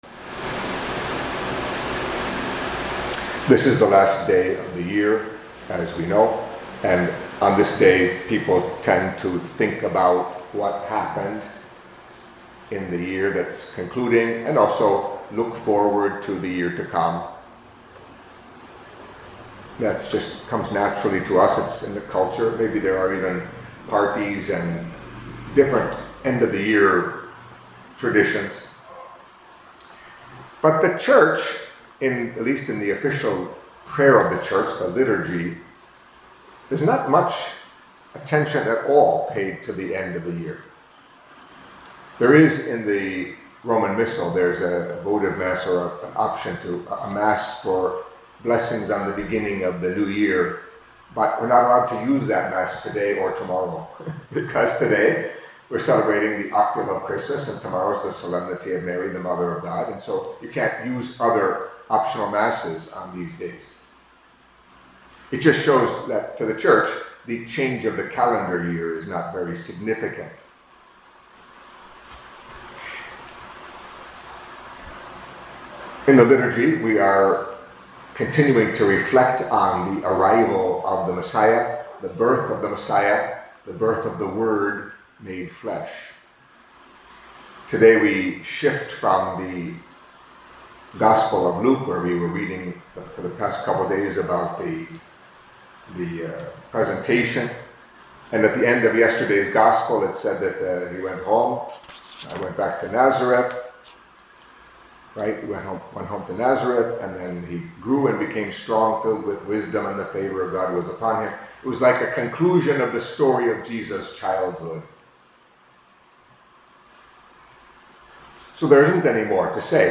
Anawim Homilies